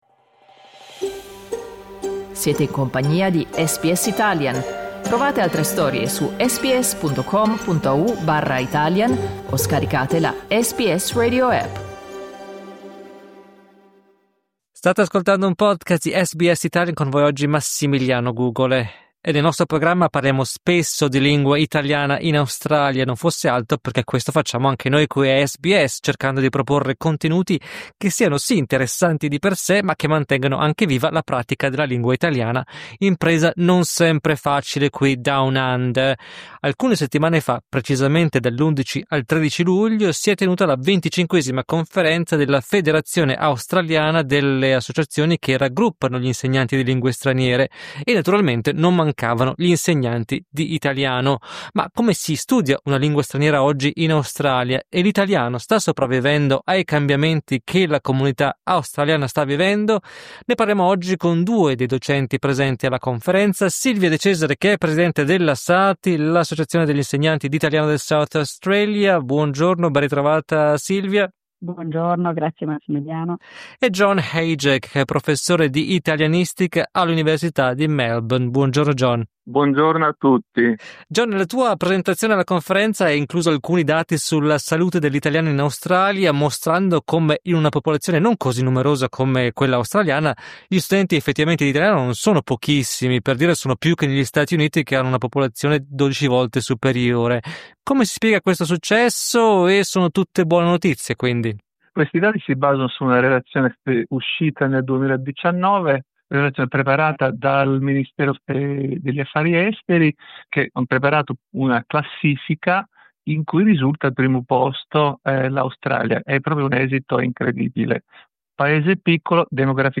E quanto è importante parlare due o più lingue? Due docenti di italiano ci raccontano le loro esperienze personali e le sfide dell'insegnamento di una lingua straniera in Australia.